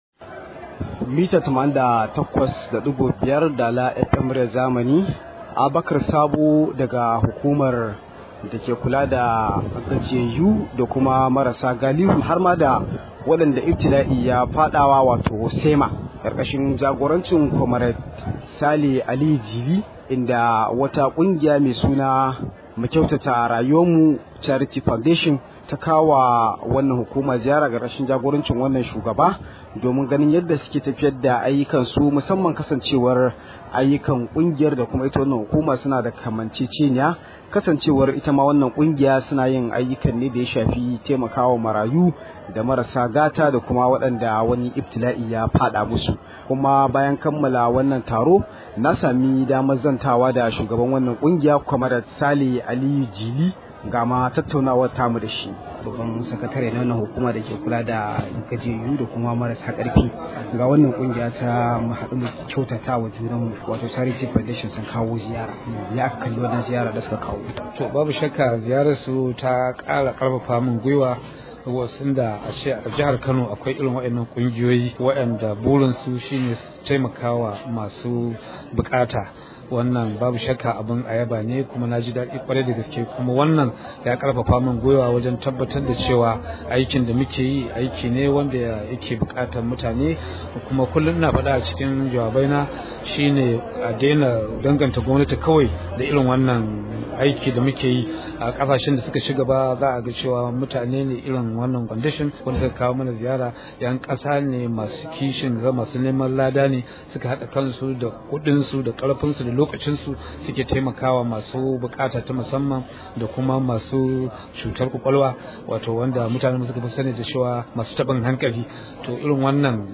Rahoto: A rinƙa tallafawa masu buƙata ta musamman domin samun rangwame – SEMA